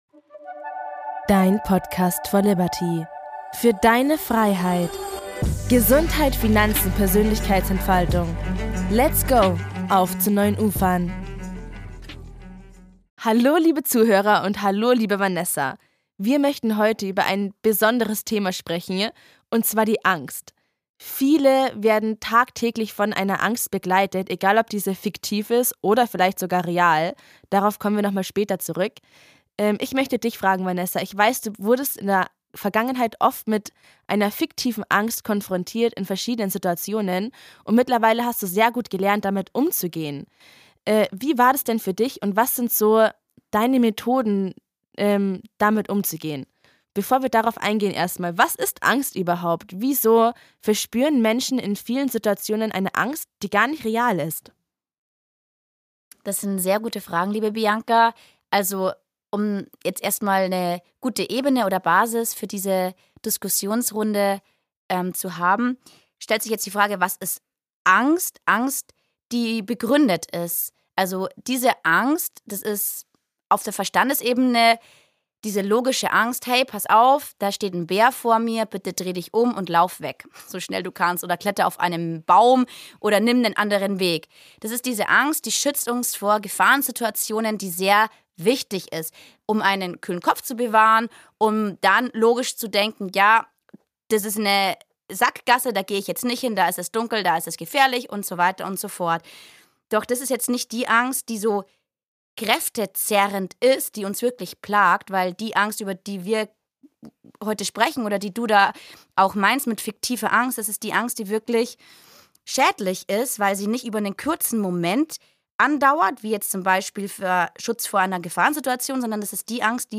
im Gespräch über die vielen Facetten der Angst!